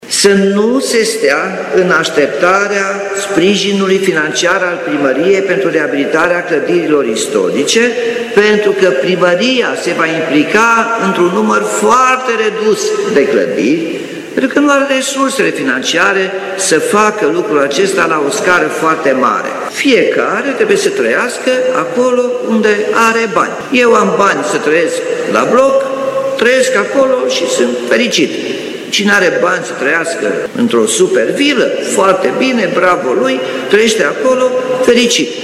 Primarul Nicolae Robu a declarat că prioritare sunt clădirile din Piața Victoriei și cele din zona Cetate, dar le-a cerut proprietarilor să nu se bazeze pe programul derulat de primărie, ci să înceapă lucrările de reabilitare din bani proprii: